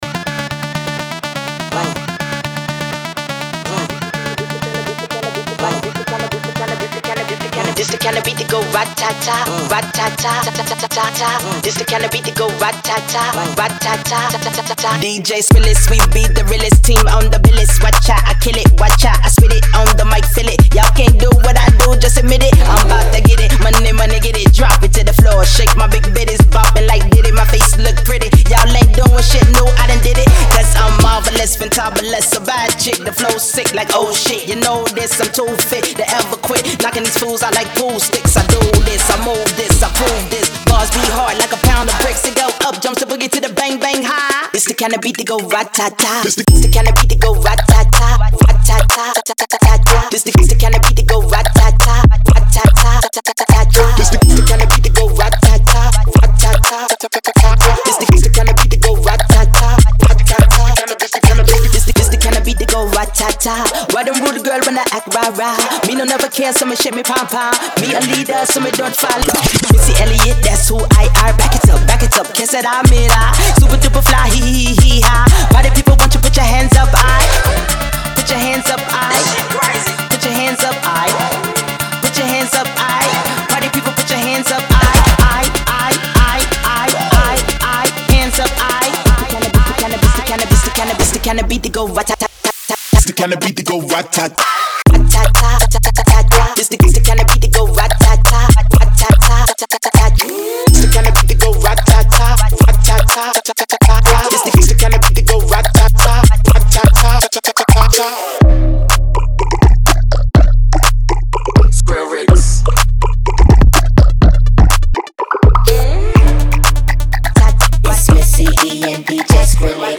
Genre : Dance